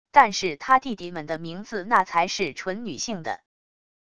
但是他弟弟们的名字那才是纯女性的wav音频生成系统WAV Audio Player